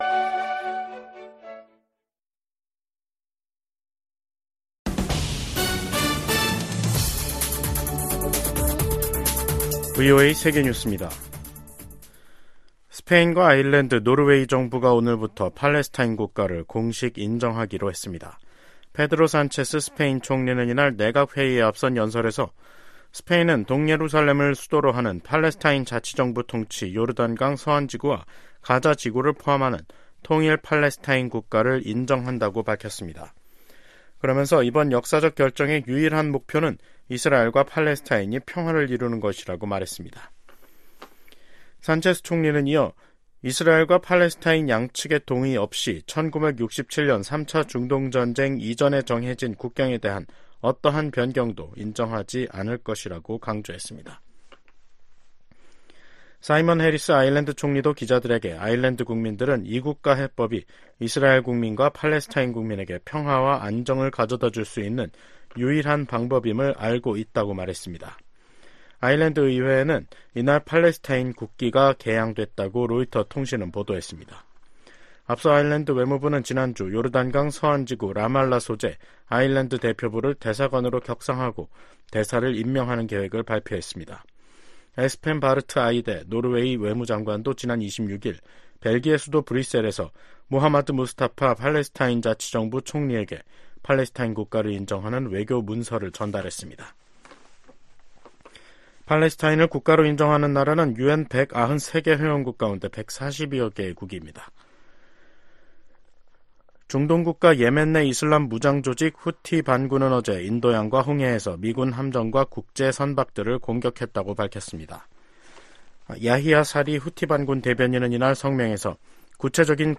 VOA 한국어 간판 뉴스 프로그램 '뉴스 투데이', 2024년 5월 28일 2부 방송입니다. 북한이 27일 밤 ‘군사 정찰위성’을 발사했지만 실패했습니다.